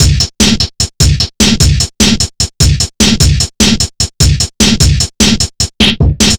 Index of /90_sSampleCDs/Zero-G - Total Drum Bass/Drumloops - 1/track 01 (150bpm)